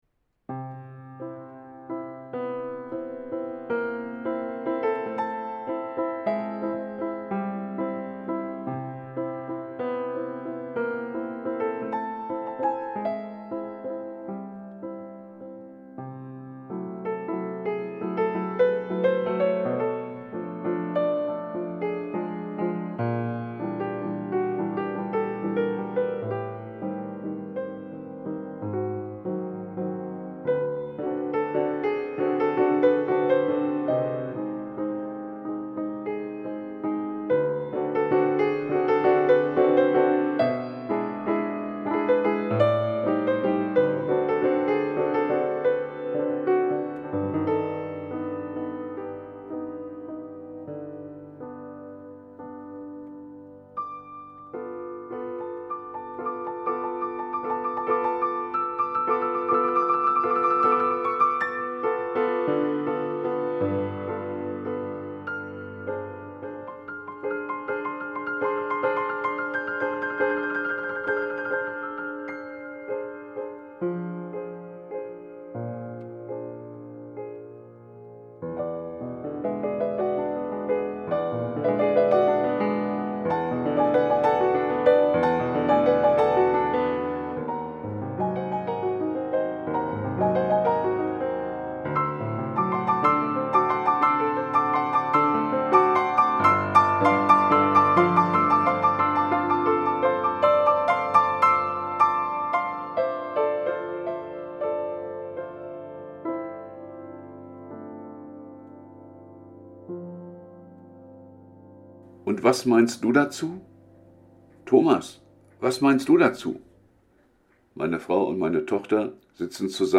Wie eine Umarmung [Andacht